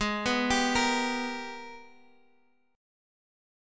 Listen to AbmM9 strummed